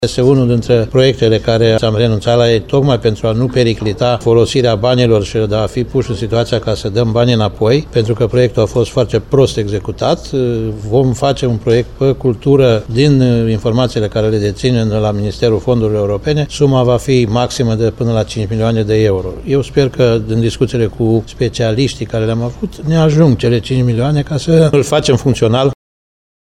Potrivit presedintelui CJT, Titu Bojin, restaurarea muzeului ar necesita 5 milioane de euro